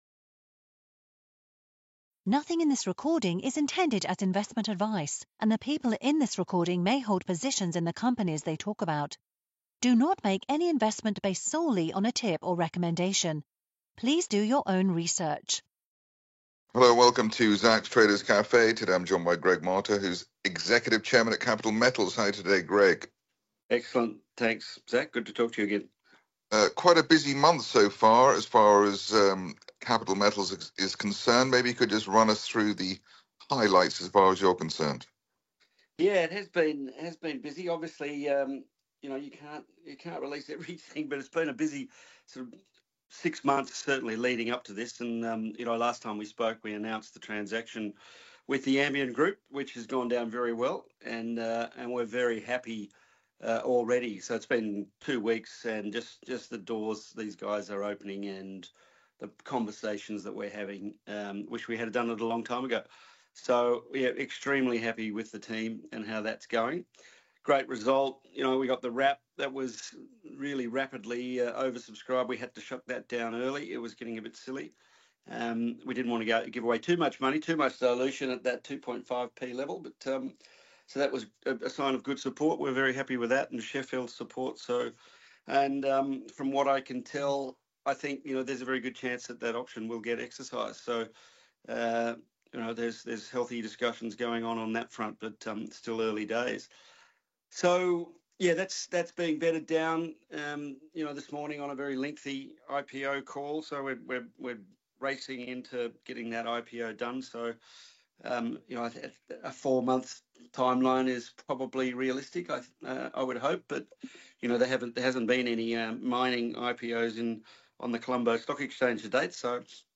Interview: Capital Metals – Advancing the Taprobane Minerals Project in Sri Lanka